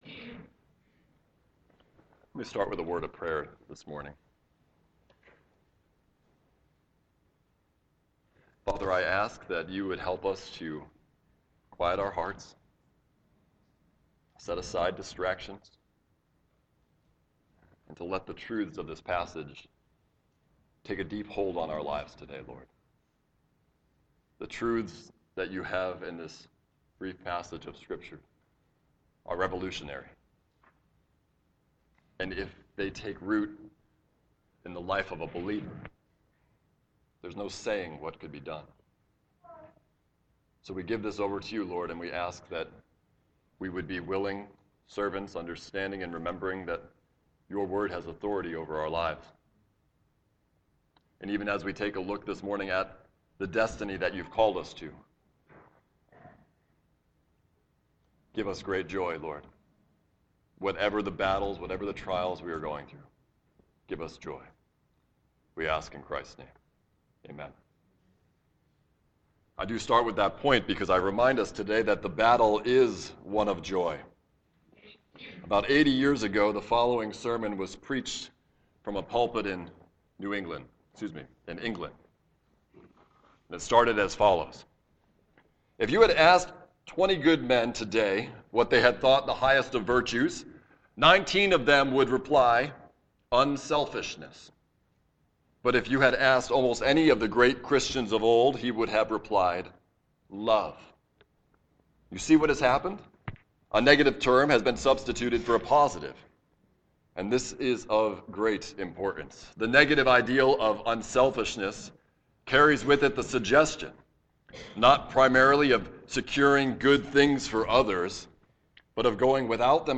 Morning Worship
Sermon